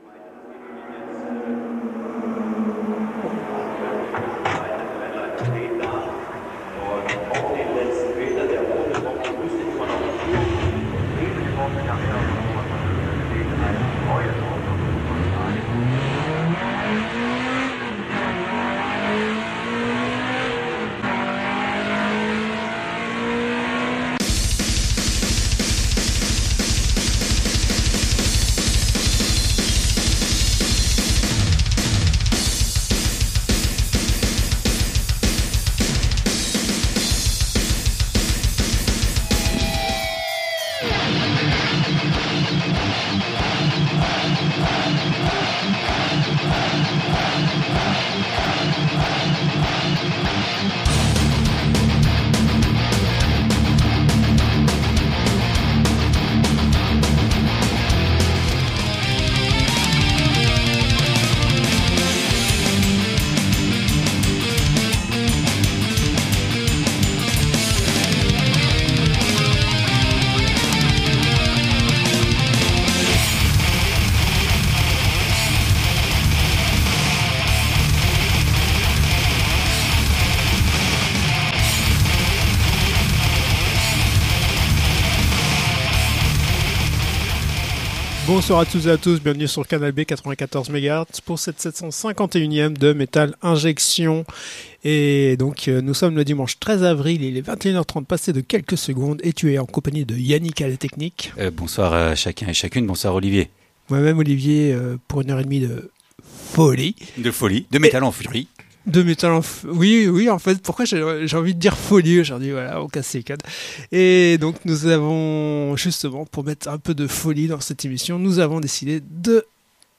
Du Hard Rock au Metal extrême, Metal Injection c'est des news, des avants premières, le classique et la reprise de la semaine, l'annonce de concerts et la promotion du Metal en Bretagne.